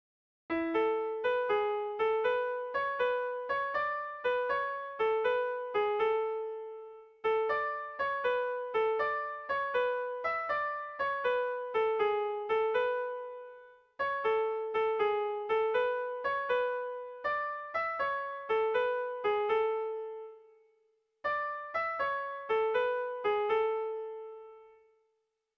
Sentimenduzkoa
Zuberoa < Euskal Herria
ABA